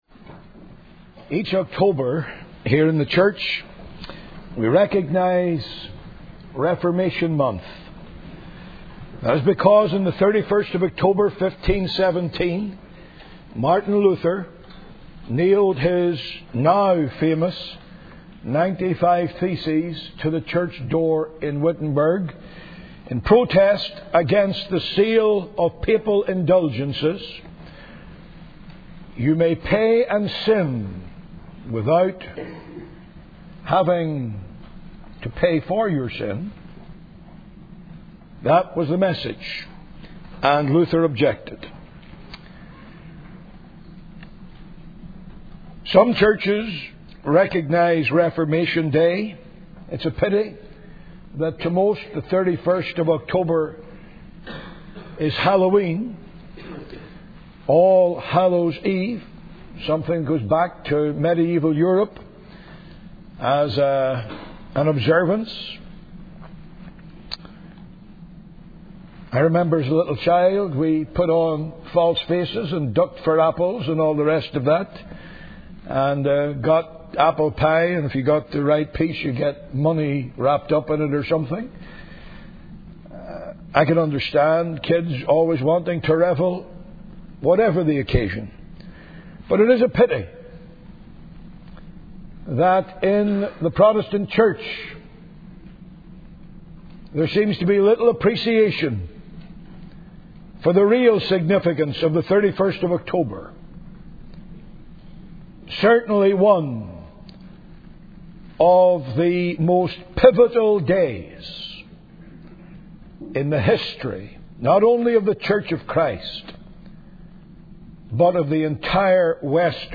In this sermon, the preacher emphasizes the importance of liberty in Christ for believers. He highlights the themes of the Gospel, including the sovereignty of God's will, the merits of Jesus Christ, and the free justification of sinners. The preacher encourages believers to stand firm in the liberty that Christ has given them and not be bound by fear or human ideas.